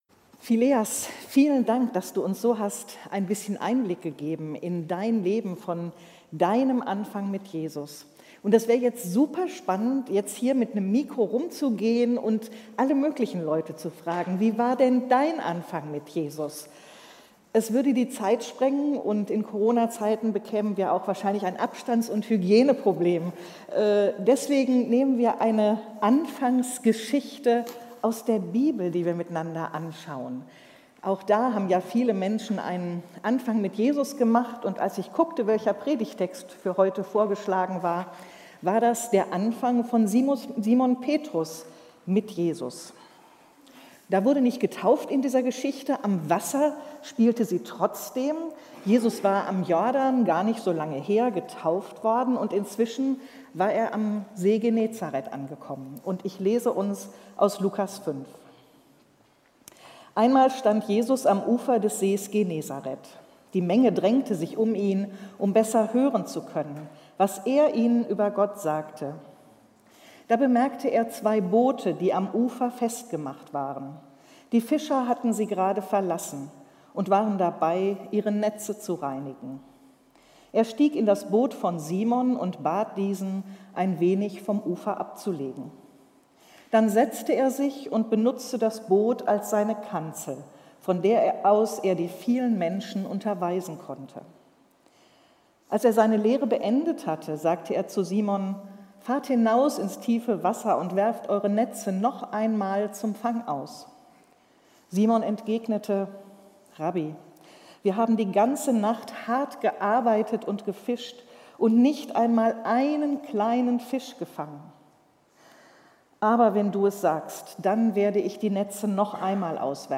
Taufgottesdienst